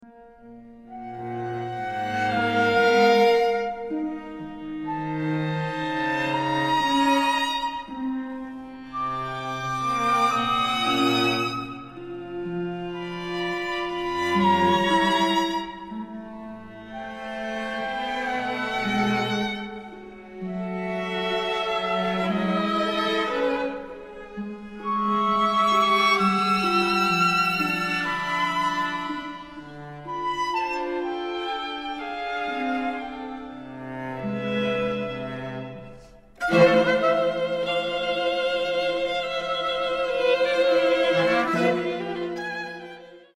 for flute, bass clarinet, harp and string quartet